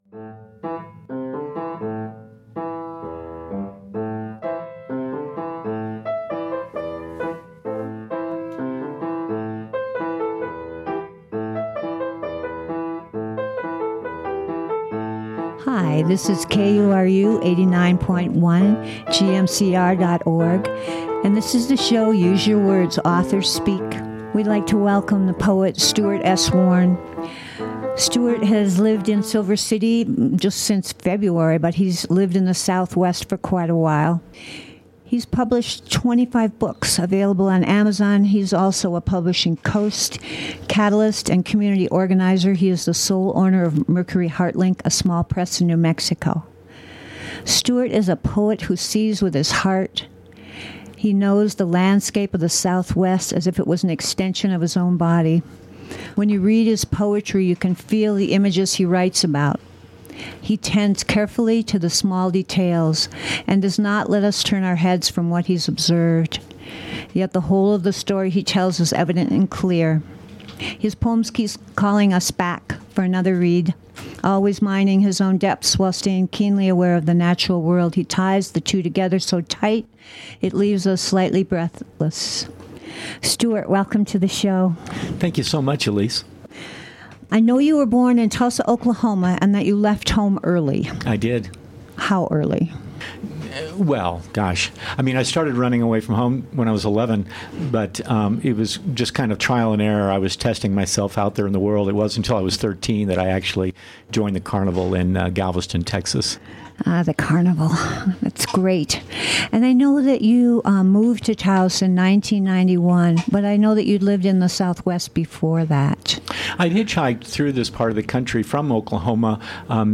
Use Your Words is broadcast live on the 2nd & 4th Fridays of the month at 4:30pm and rebroadcast the following Fridays at the same time.